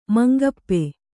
♪ mangappe